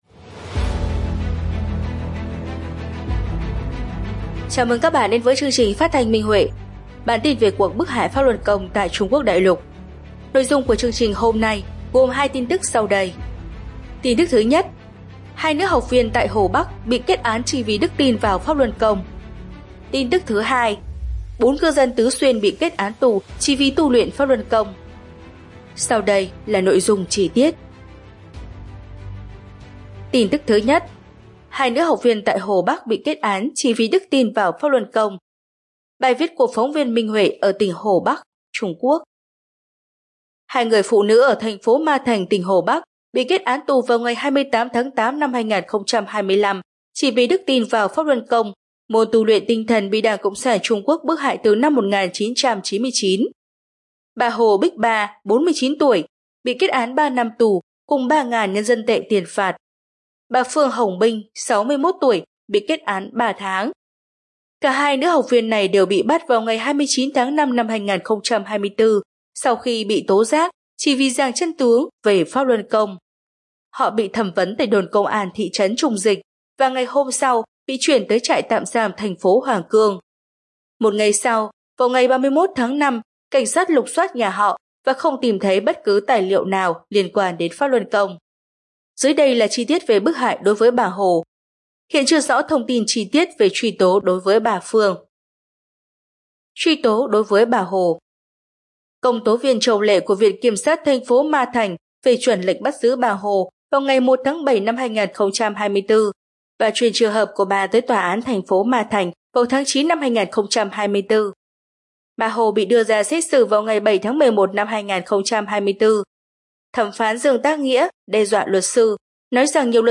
Chương trình phát thanh số 242: Tin tức Pháp Luân Đại Pháp tại Đại Lục – Ngày 16/9/2025